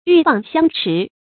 鷸蚌相持 注音： ㄧㄩˋ ㄅㄤˋ ㄒㄧㄤ ㄔㄧˊ 讀音讀法： 意思解釋： 《戰國策·燕策二》記載：蚌張開殼曬太陽，鷸去啄它，嘴被蚌殼夾住，兩方都不相讓。